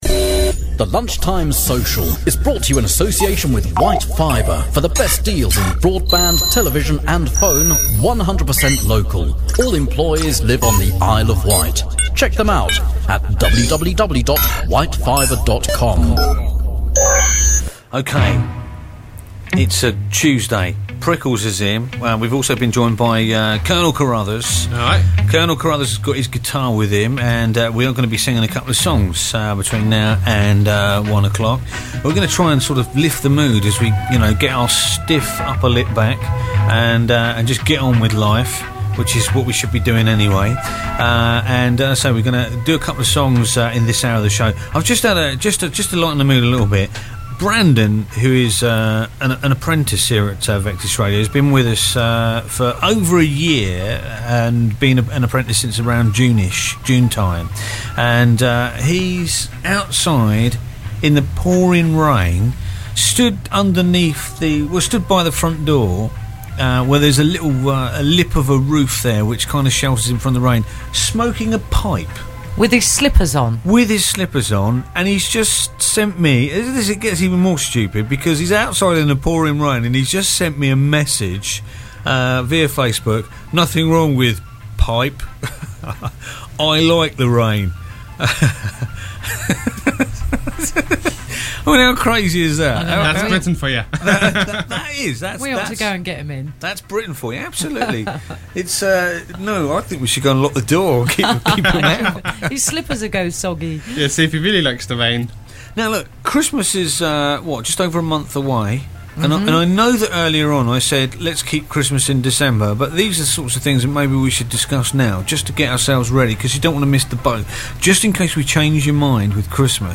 The Lunchtime Social Debate - Do You Still Send Christmas Cards